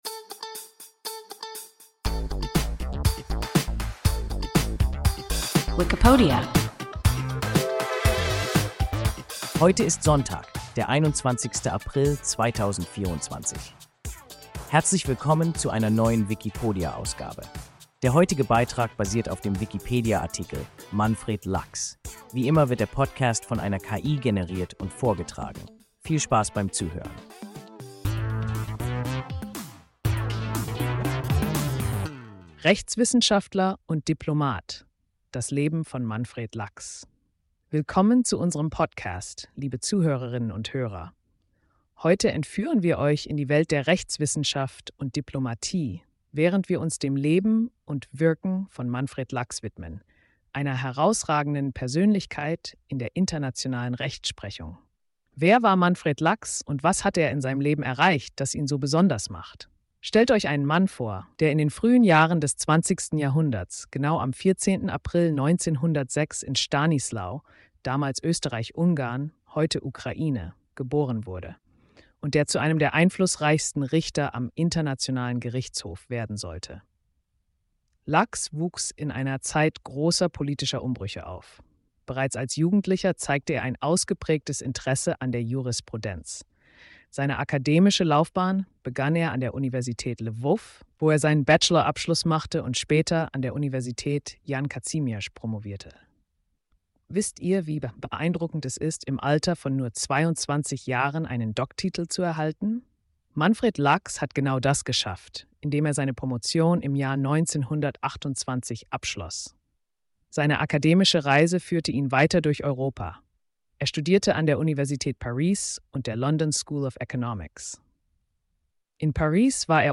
Manfred Lachs – WIKIPODIA – ein KI Podcast